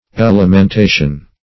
Search Result for " elementation" : The Collaborative International Dictionary of English v.0.48: Elementation \El`e*men*ta"tion\, n. Instruction in the elements or first principles.